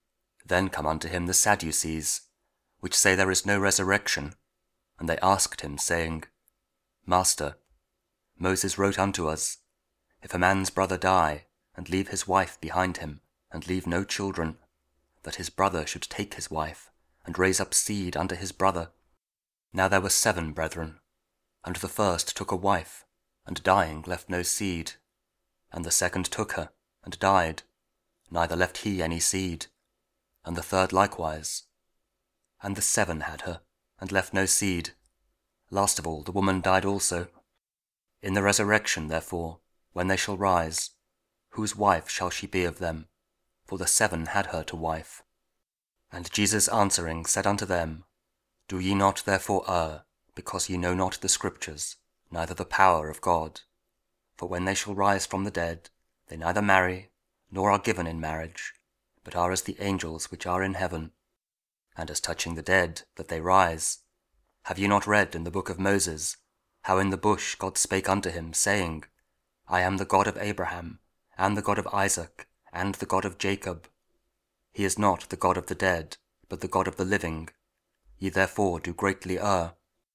Mark 12: 18-27 – Week 9 Ordinary Time, Wednesday (Audio Bible KJV, Spoken Word)